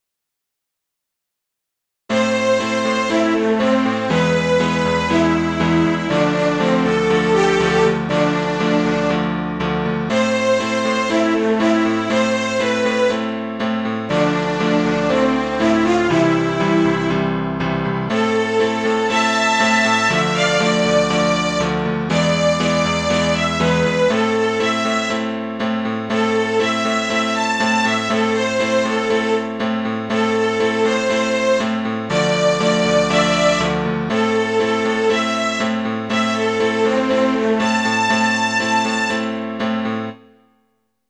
【拍子】4/4